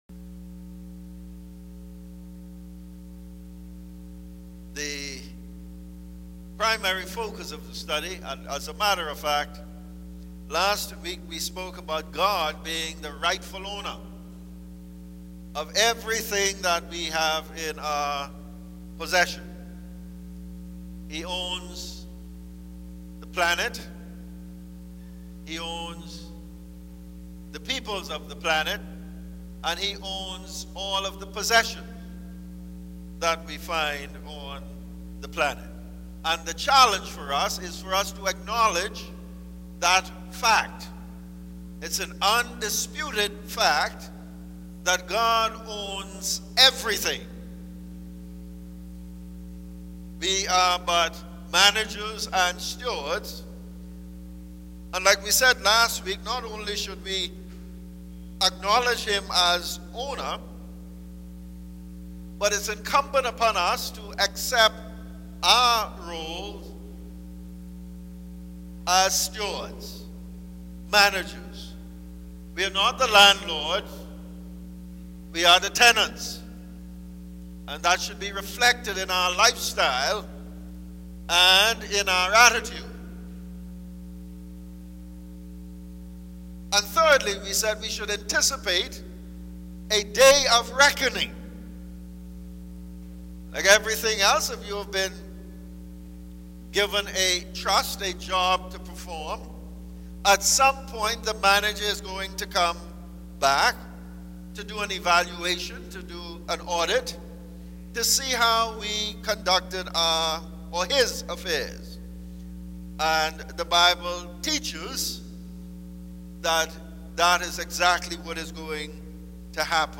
Sermons «